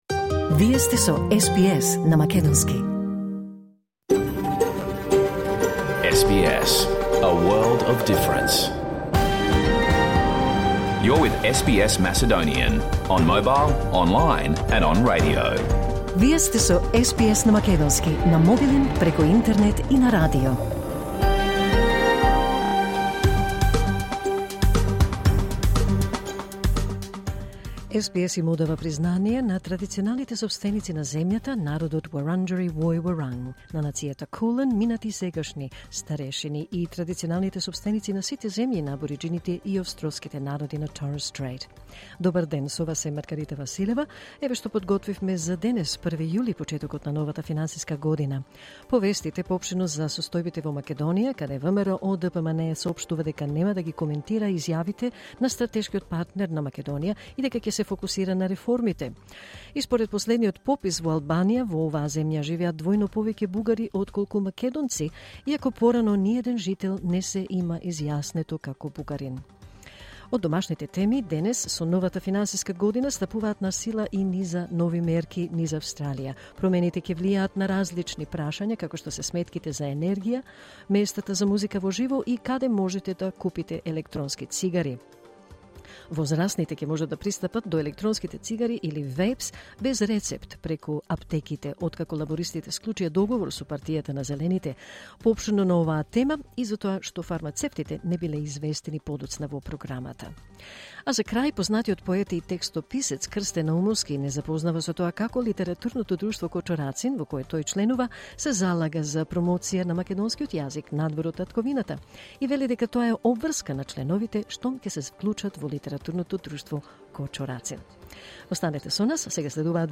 SBS Macedonian Program Live on Air 1 July 2024